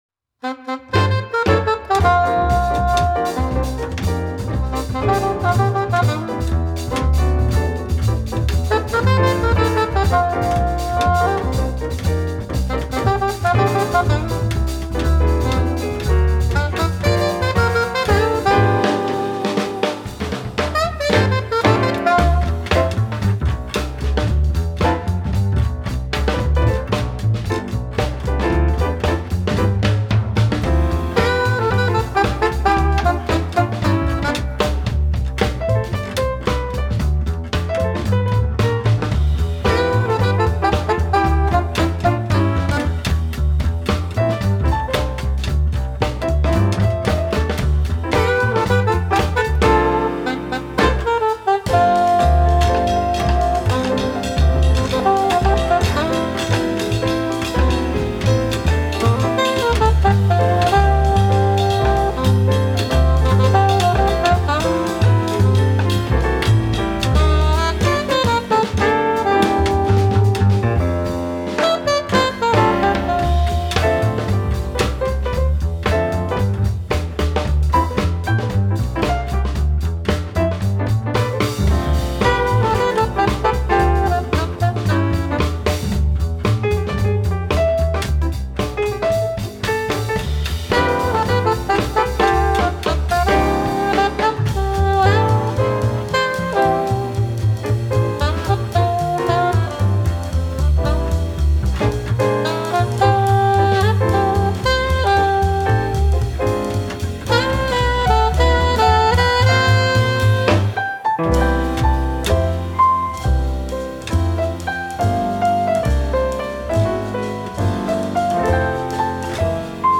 Holiday Quartet
original fusion arr.